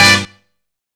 STAB EXTRA 3.wav